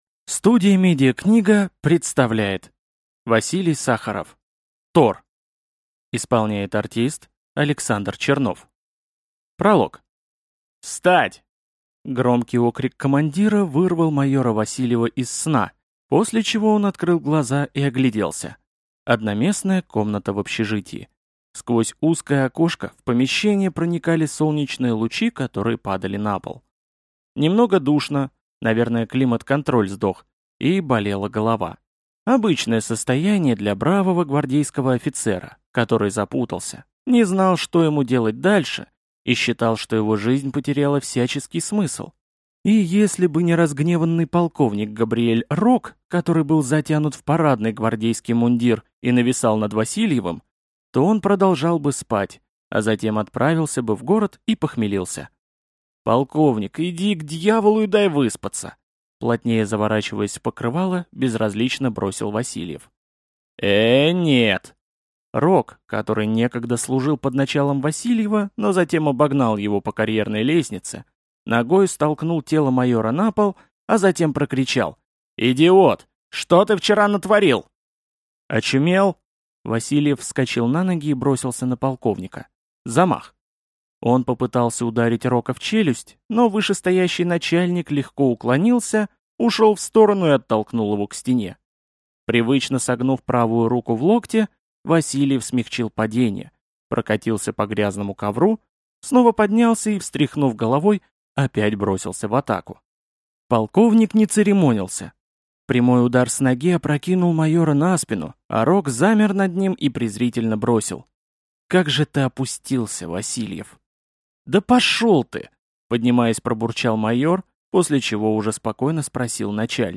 Аудиокнига Тор | Библиотека аудиокниг